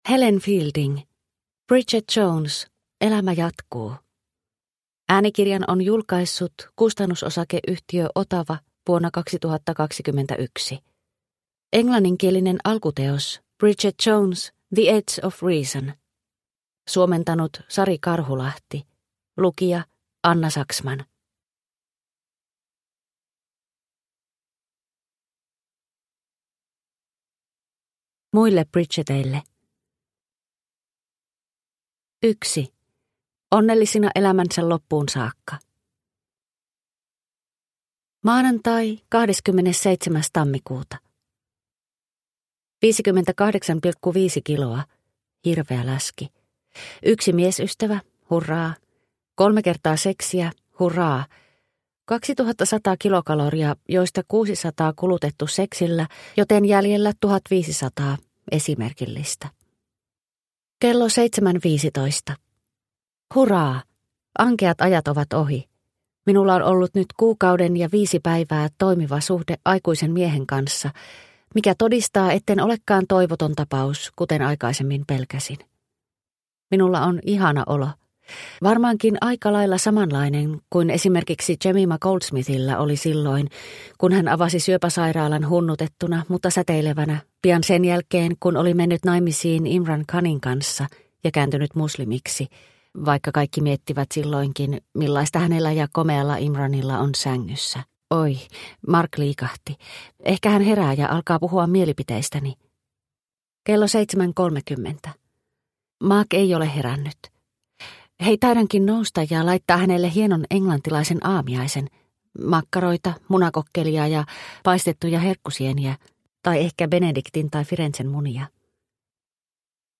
Bridget Jones - elämä jatkuu – Ljudbok – Laddas ner